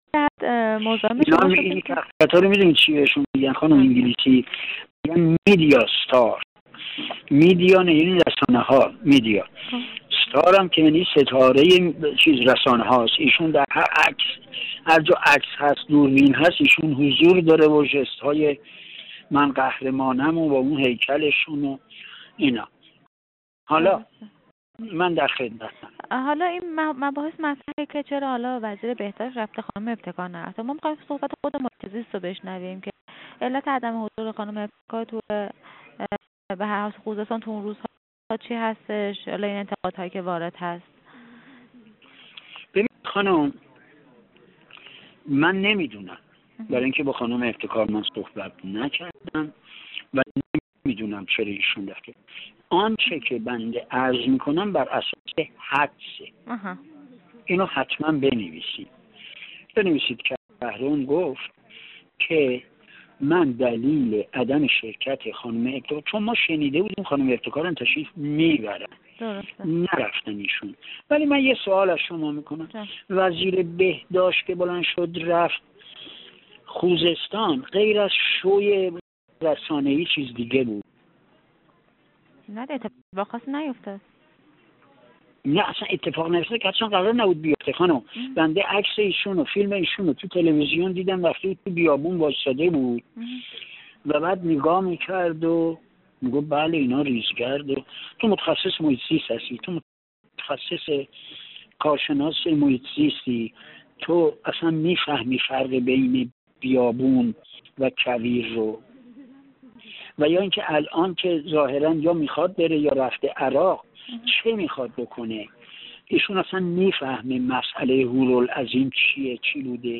فایل صوتی مصاحبه اسماعیل کهرم، مشاور رئیس سازمان محیط زیست را ضمن عذرخواهی به دلیل انتشار برخی الفاظ ایشان می‌توانید بشنوید: